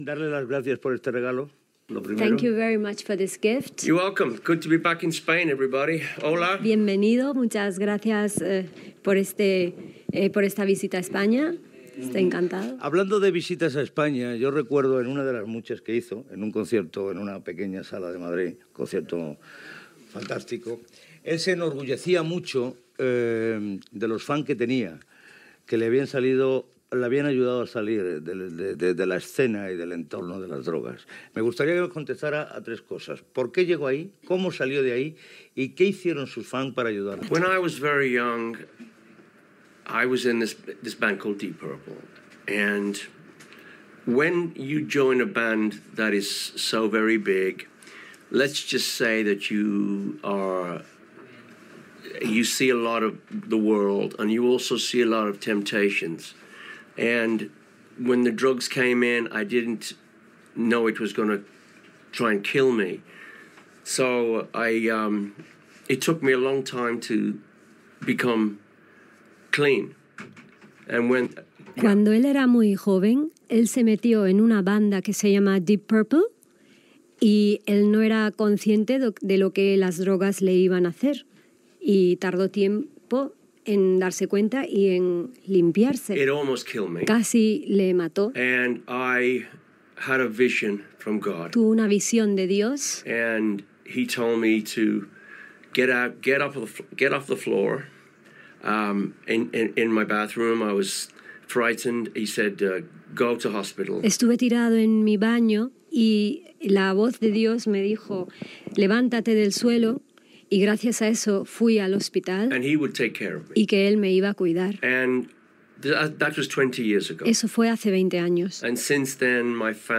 Entrevista al música Glenn Hughes i interpretació d'un tema en directe a l'estudi
Musical